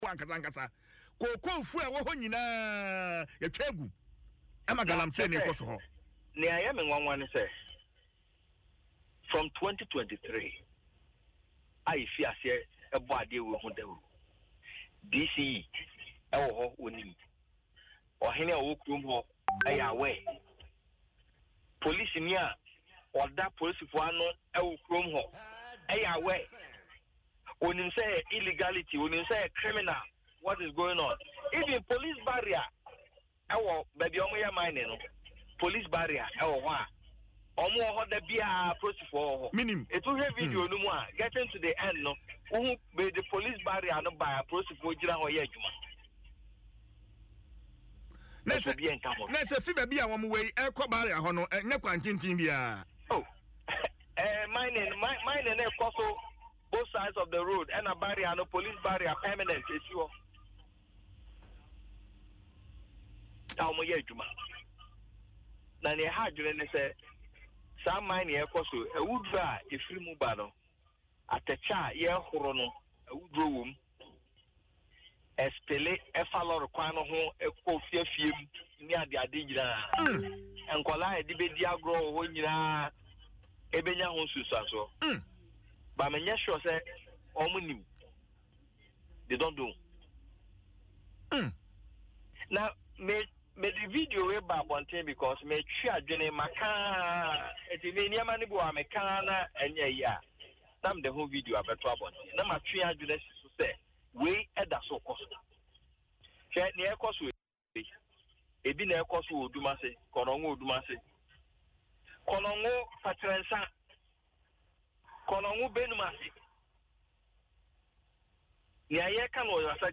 Speaking on Adom FM’s Dwaso Nsem